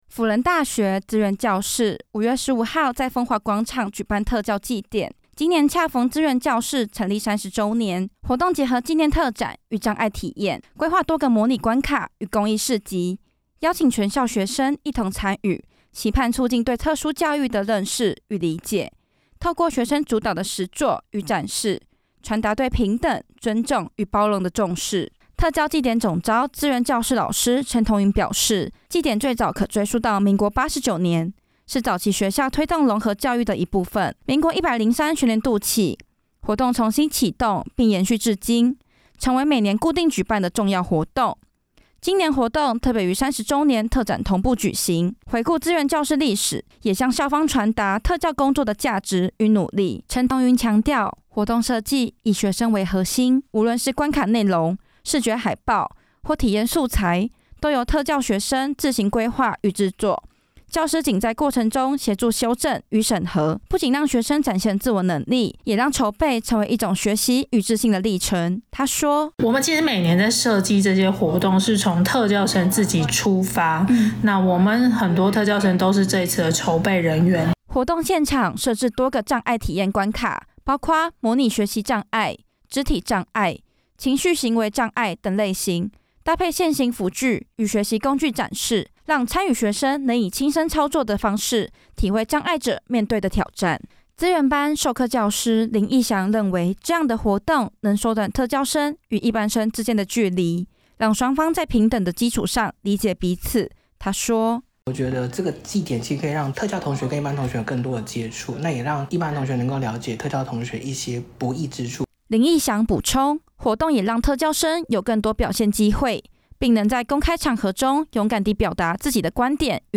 新聞話帶_mixdown.mp3